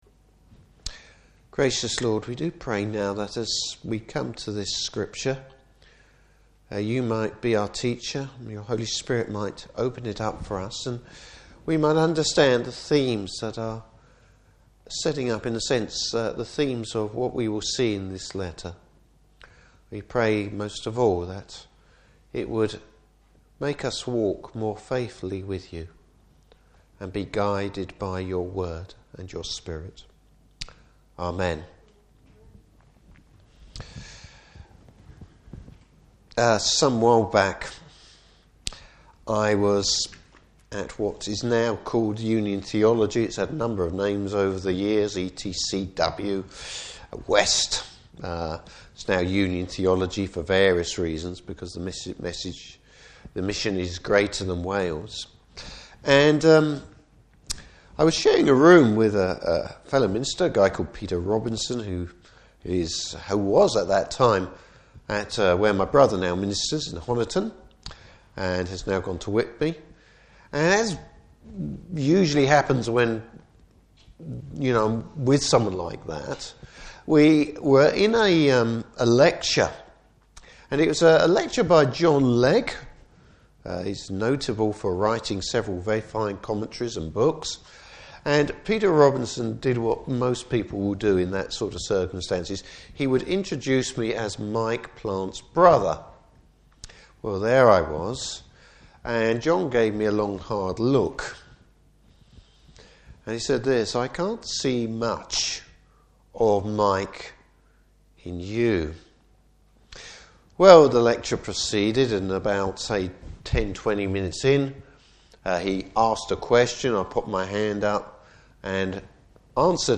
Service Type: Evening Service Bible Text: 1 John 1:1-4.